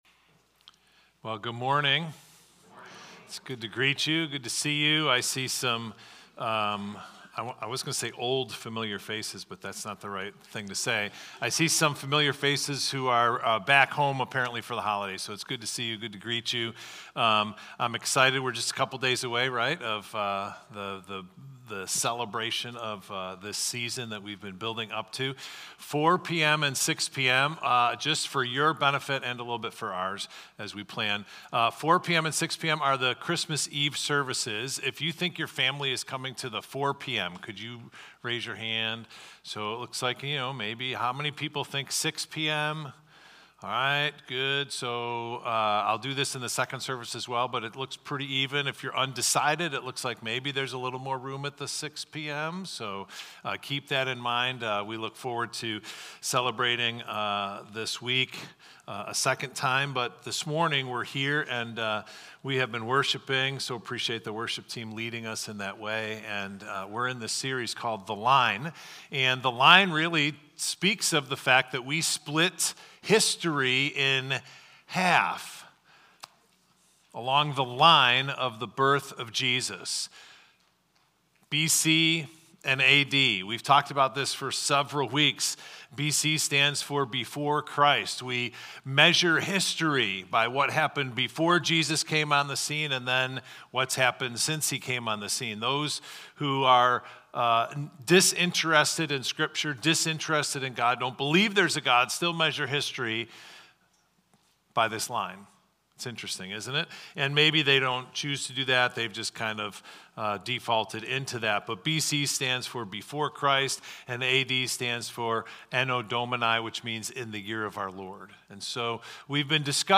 Victor Community Church Sunday Messages / The Line: Jesus and the New Covenant (December 22nd, 2024)